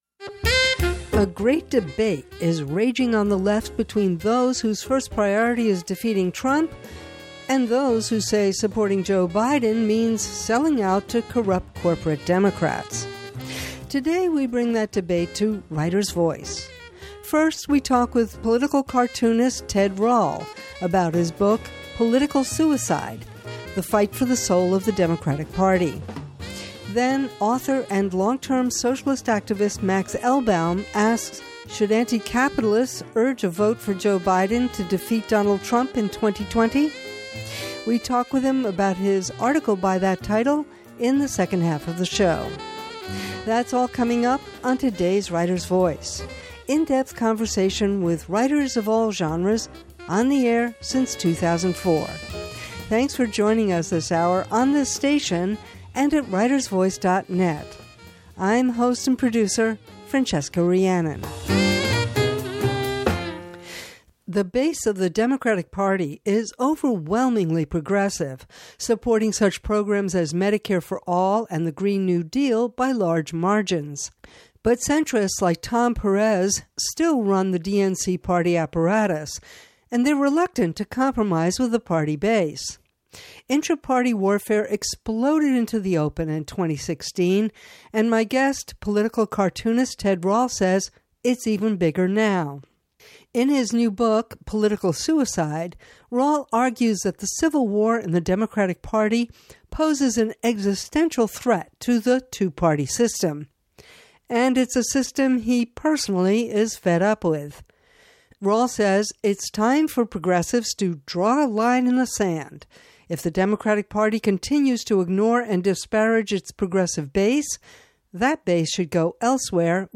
Writer’s Voice — in depth conversation with writers of all genres, on the air since 2004.